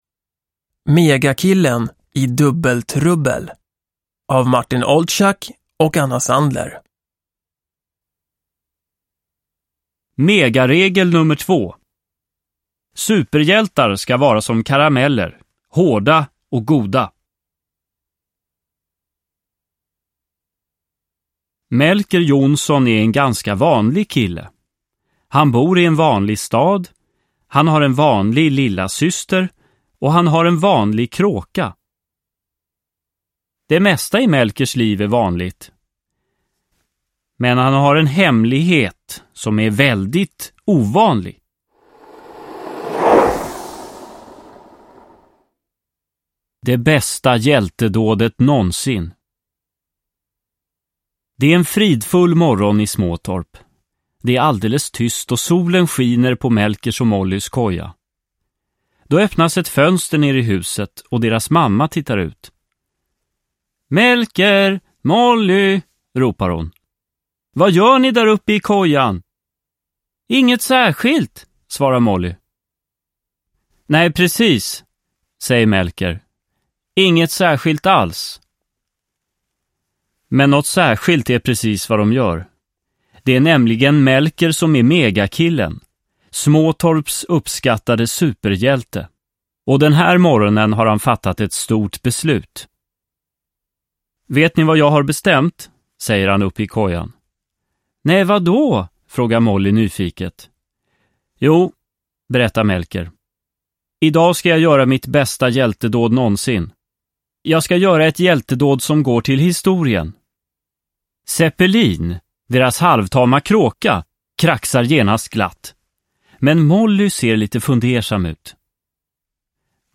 Megakillen i dubbeltrubbel – Ljudbok – Laddas ner